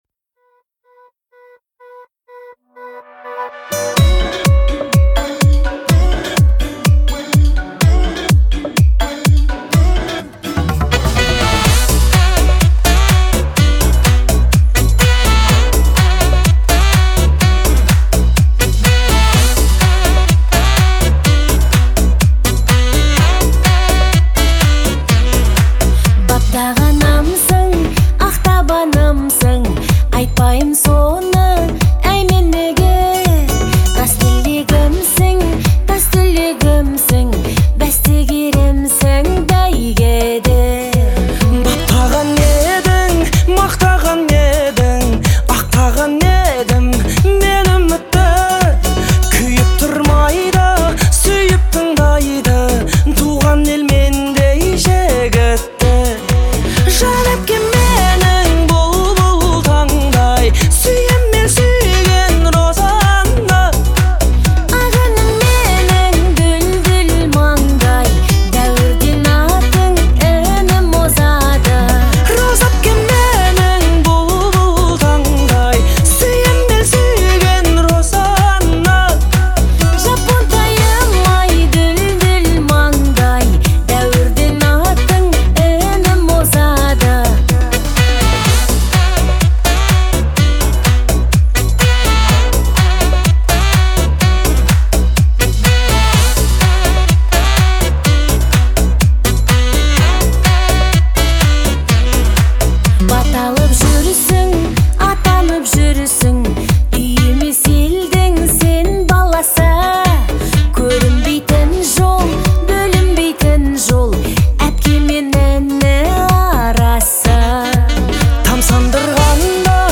это трогательная композиция в жанре казахской поп-музыки